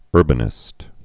(ŭrbə-nĭst)